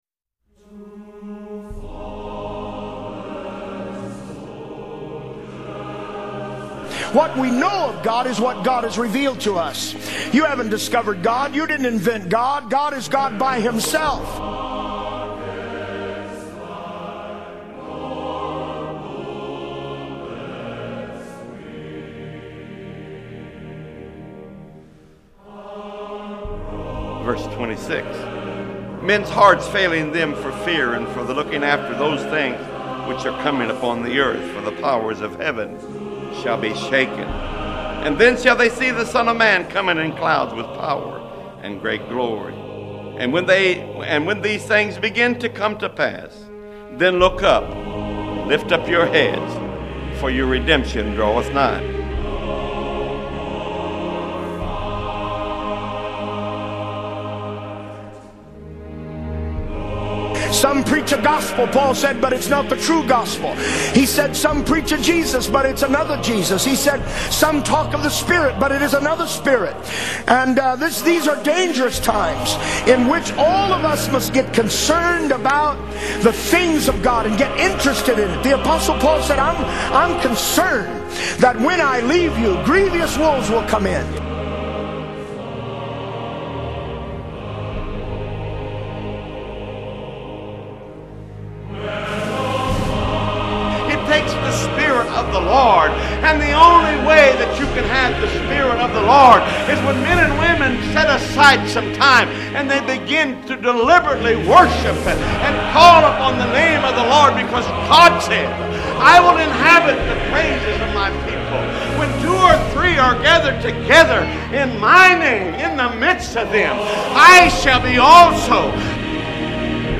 God Is Good (Compilation)
Sermon Outline